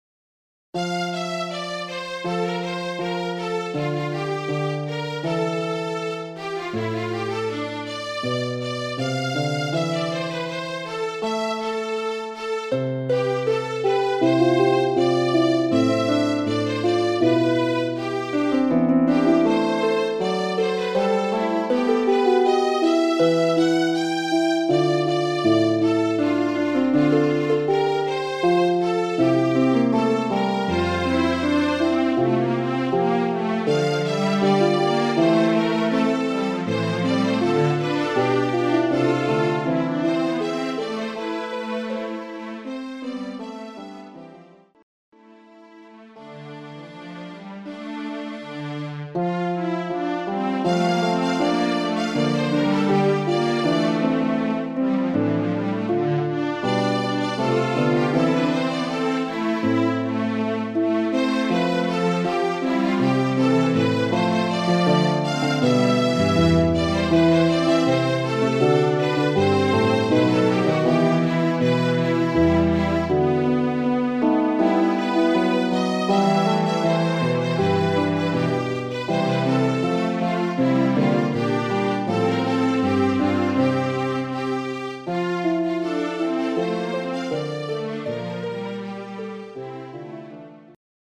Sound  (Tonprobe), synthesized